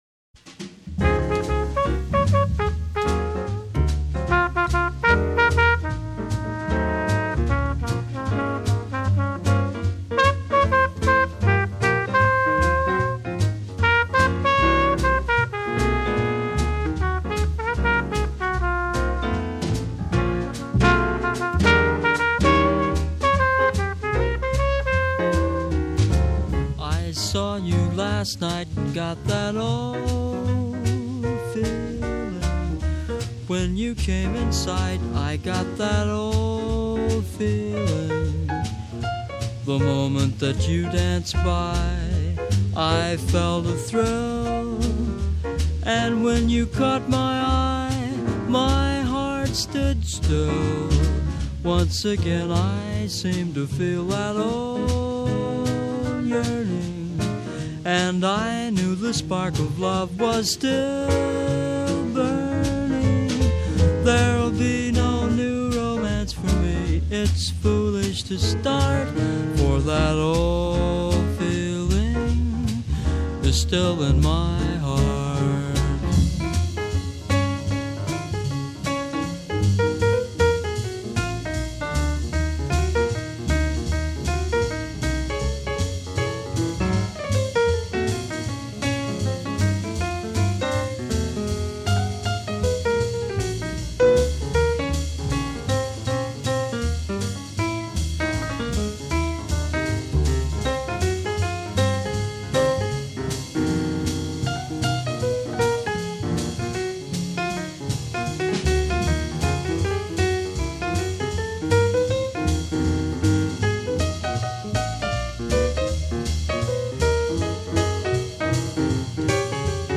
Cool Jazz, Vocal Jazz